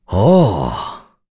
Bernheim-Vox_Happy4_kr.wav